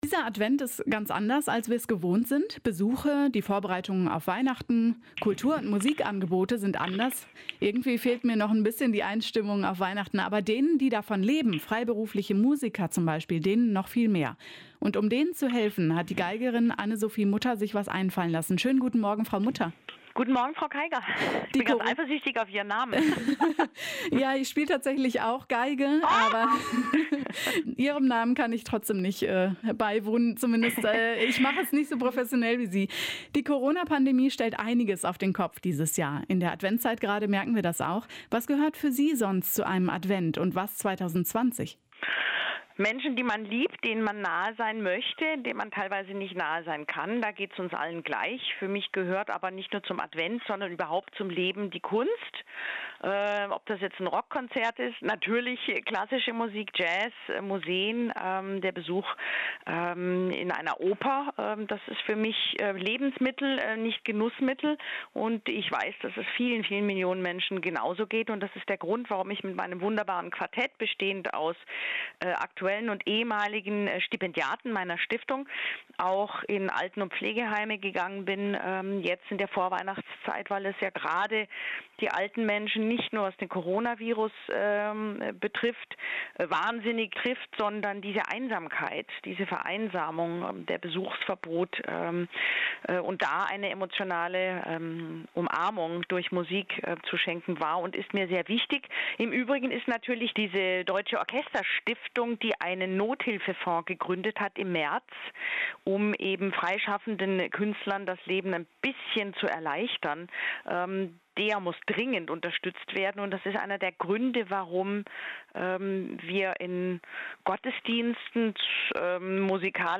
Ein Interview mit Anne-Sophie Mutter (Geigerin)